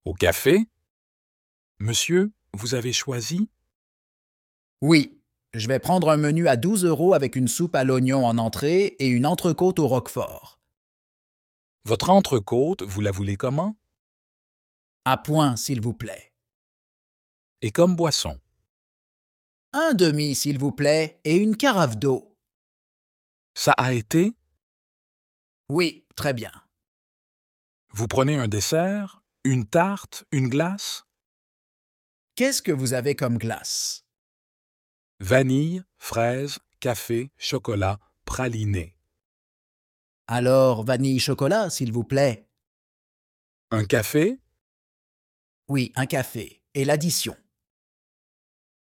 Dialogue FLE A2 – Au café : commandez un repas en français, choisissez une cuisson, une boisson, un dessert et demandez l’addition. Dialogue simple pour les débutants.
Dialogue en français – Au café (Niveau A2)